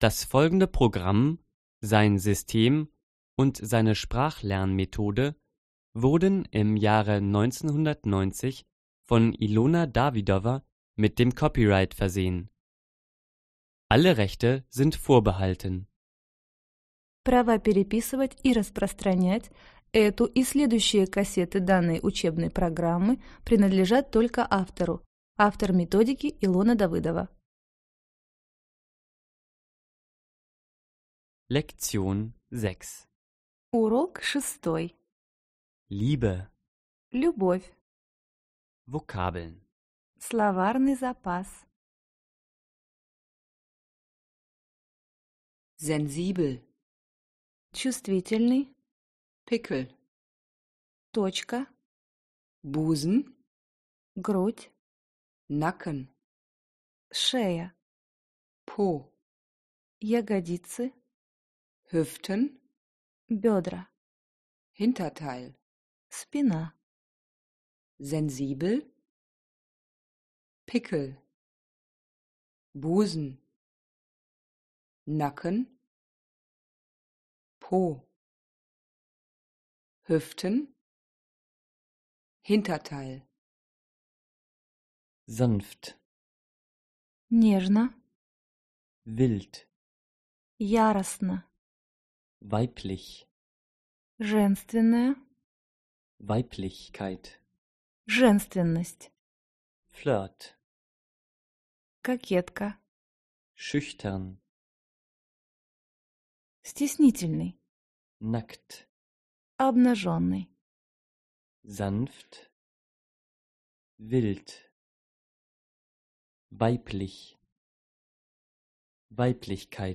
Аудиокнига Разговорно-бытовой немецкий язык. Диск 6 | Библиотека аудиокниг